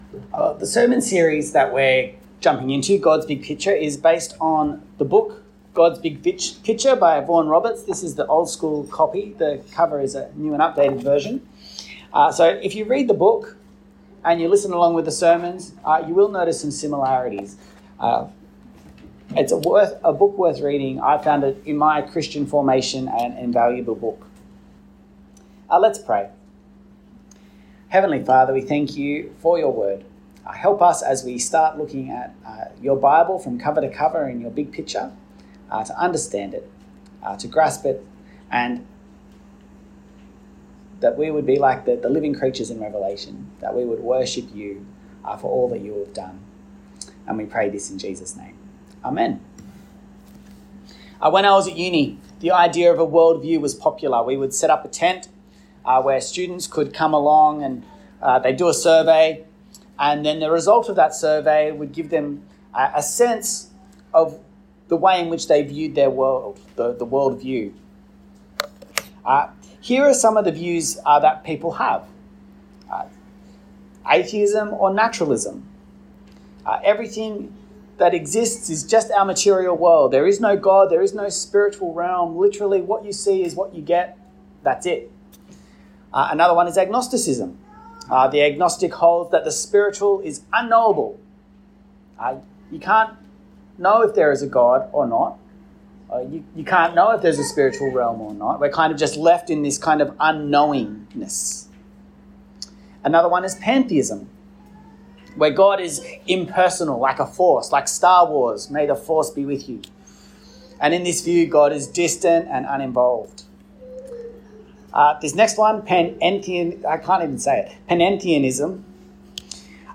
The Pattern of Kingdom - Sermon.mp3